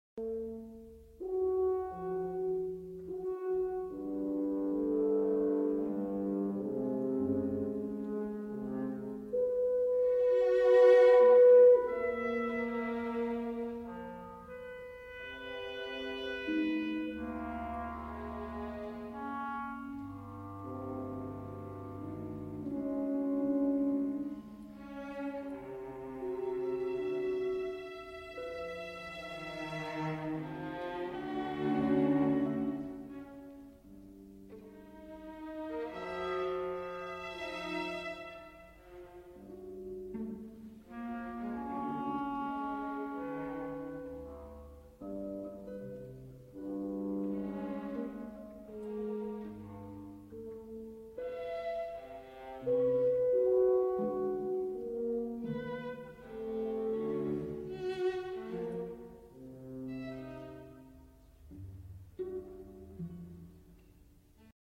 Excerpt from 'Symphony No. 21 - Opening' by Anton Webern (1927-1928).